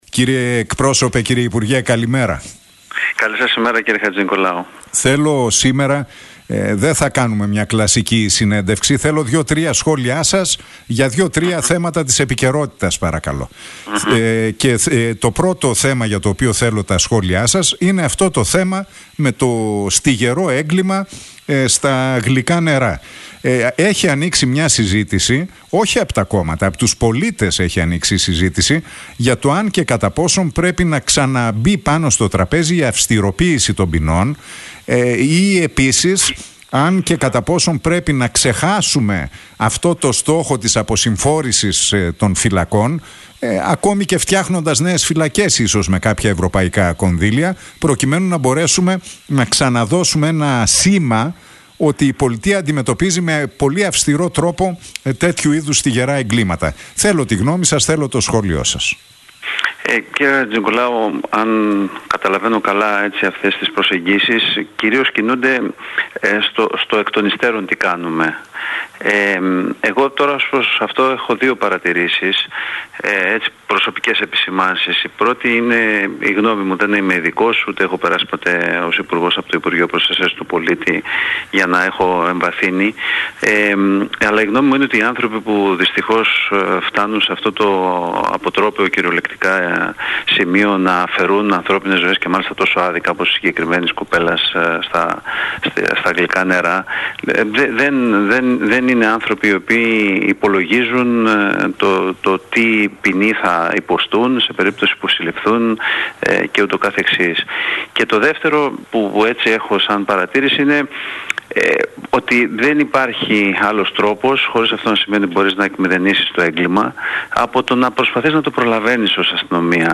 Ο κοινοβουλευτικός εκπρόσωπος του ΣΥΡΙΖΑ, Γιάννης Ραγκούσης, μιλώντας στον Realfm 97,8 και στην εκπομπή του Νίκου Χατζηνικολάου...